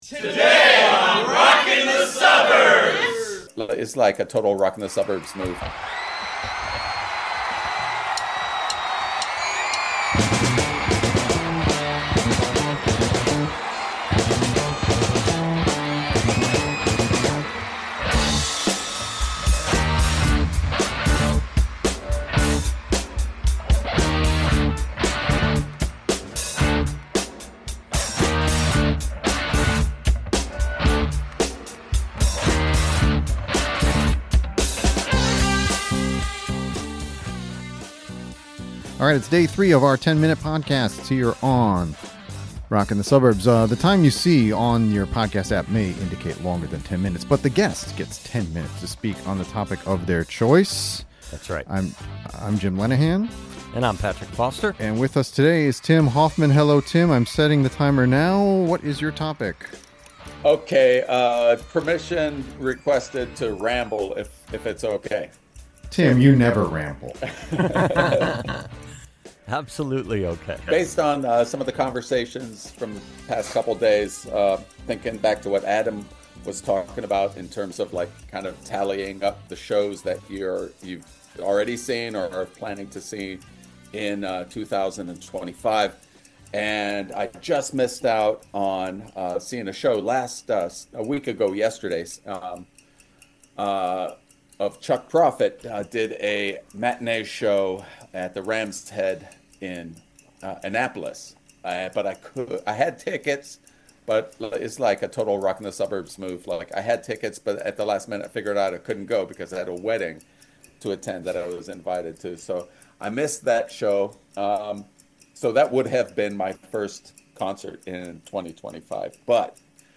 We recently held a virtual podcast recording where we invited participants to come up with a topic of their choice.